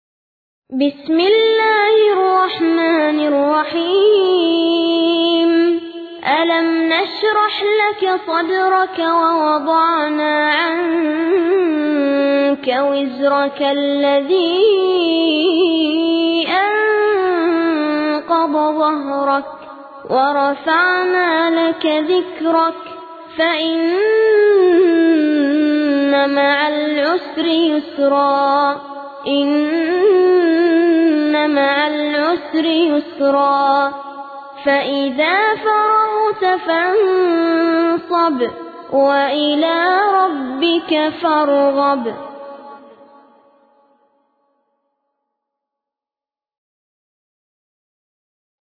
فایل صوتی تلاوت سوره شرح